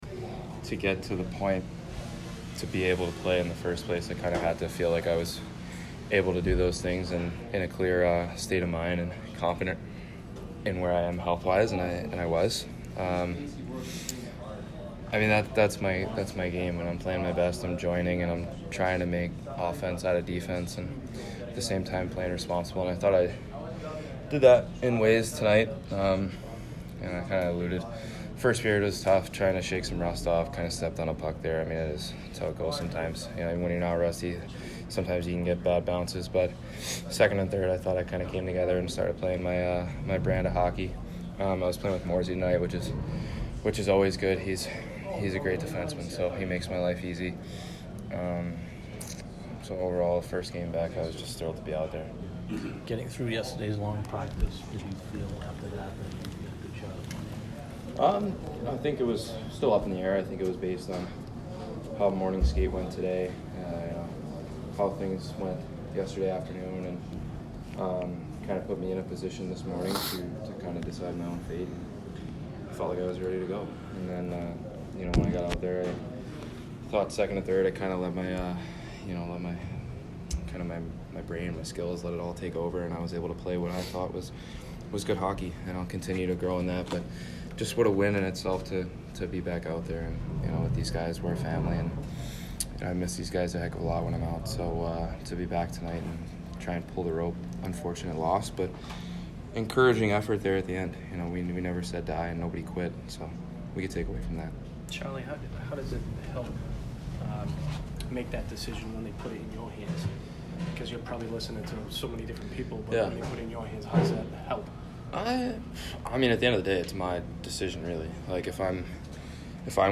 Charlie McAvoy post-game 12/06